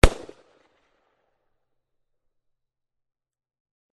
rptstungunshot2.mp3